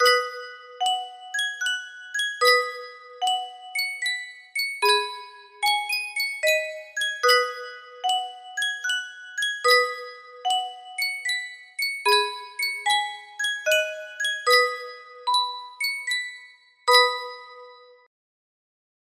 Sankyo Music Box - Arirang FDE music box melody
Full range 60